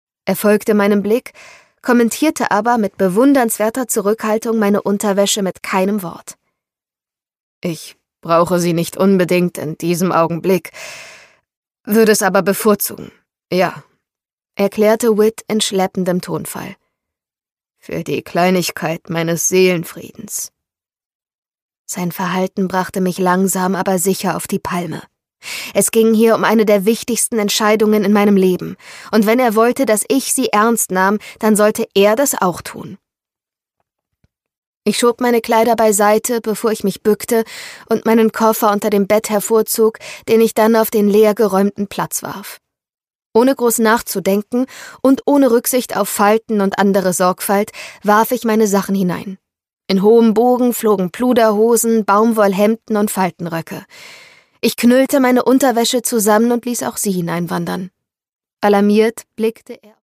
Isabel Ibañez: Where the Library hides - Geheimnisse des Nil, Band 2 (Ungekürzte Lesung)
Produkttyp: Hörbuch-Download